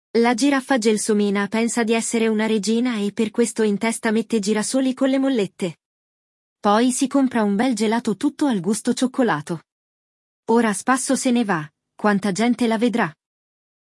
Quando o G vem acompanhado do A, O ou U, o som é igual ao do português.
Agora, quando temos as letras E ou I seguidas do G, o som vai ser de /dje/ e /dji/.